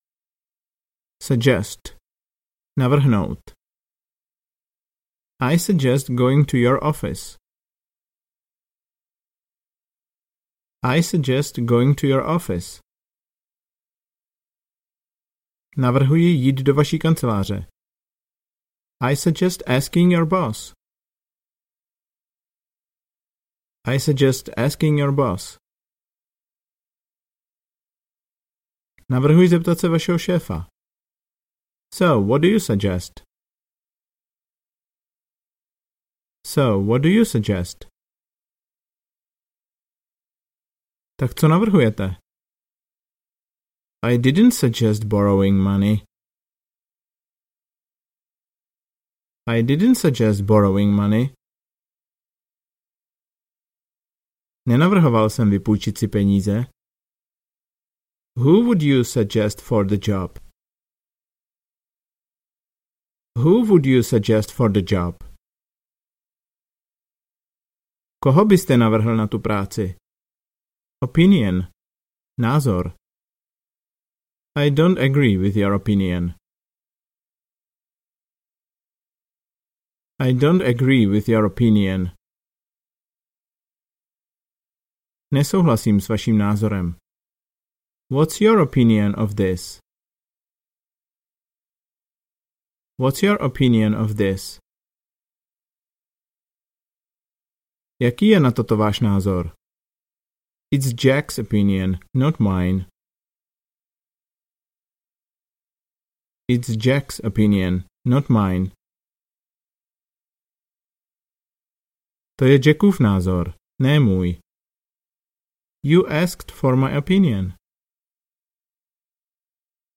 Ukázka z knihy
Dvakrát anglicky a jednou česky.